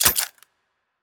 UI_Click.wav